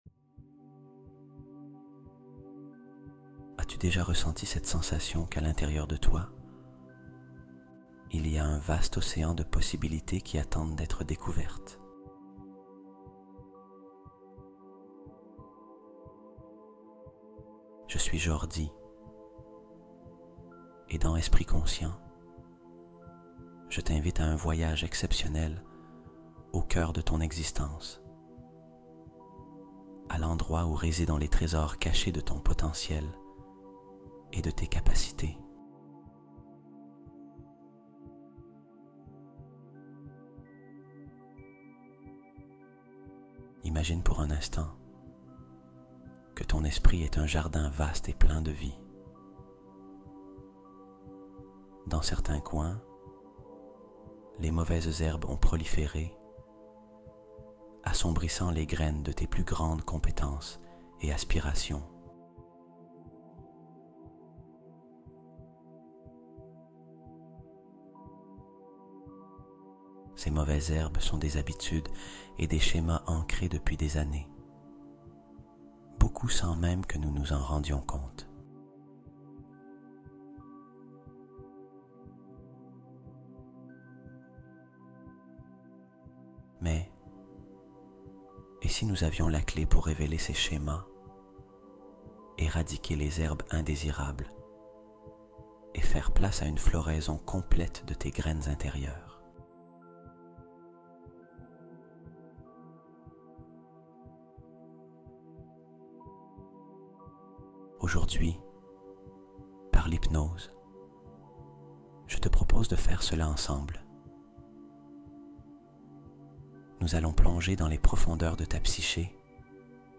Méditation guidée pour dormir – la clé dorée du repos profond